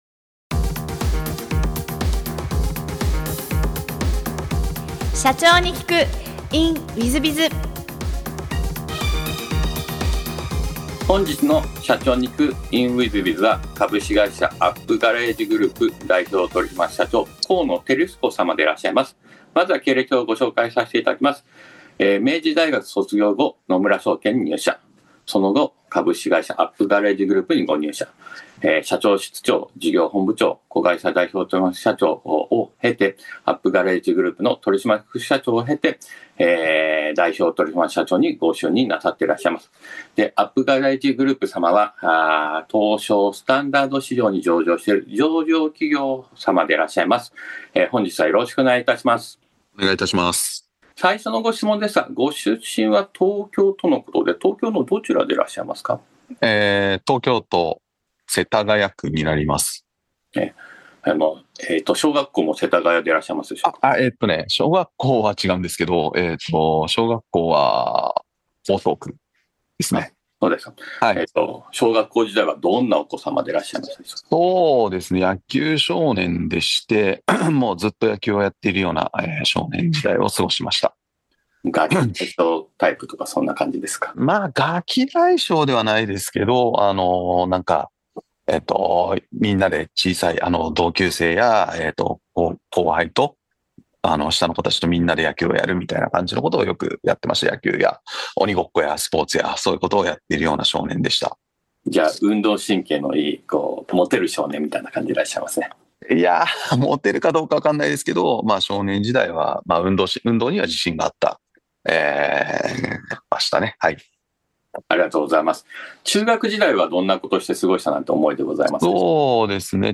中古車業界で業績を伸ばし続けるお姿から経営のヒントが得られます。ぜひ、インタビューをお聞きください。